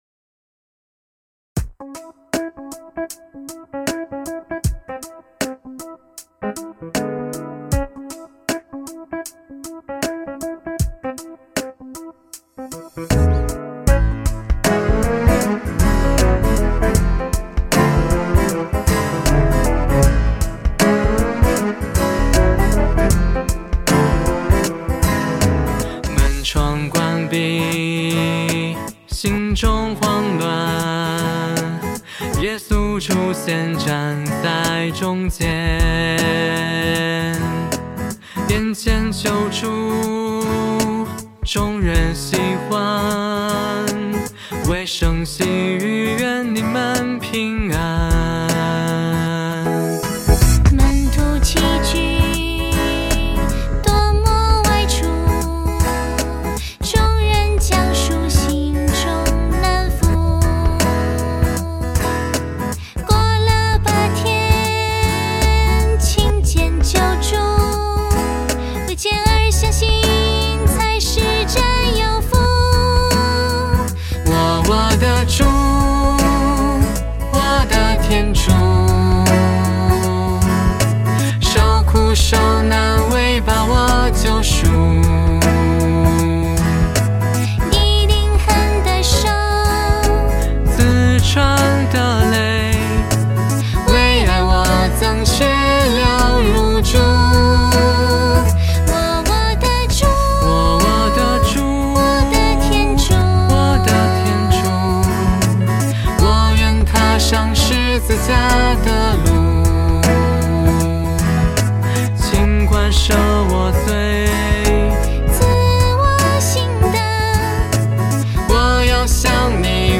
【主日赞歌】| 我的主！我的天主！